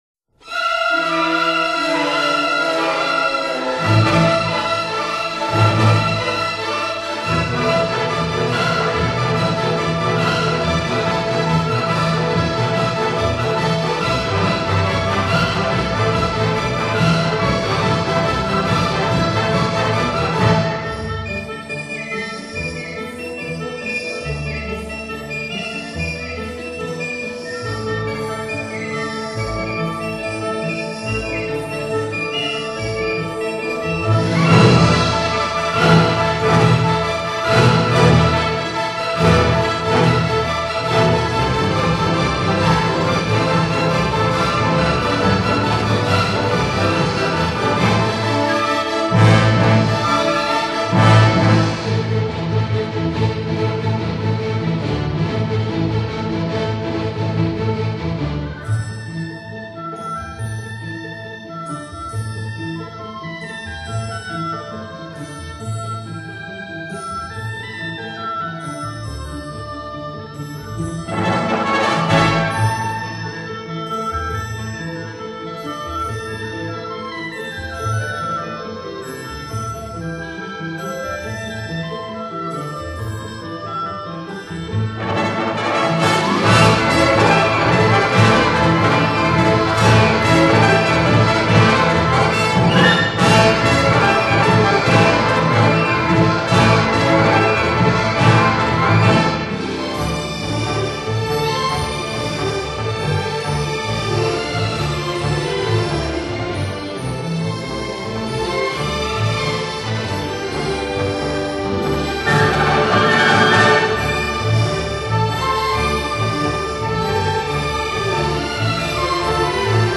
交响套曲
其中有三个不同性格的舞蹈主题穿插出现；粗犷的男子舞蹈、柔美的女子舞蹈以及欢腾的群舞。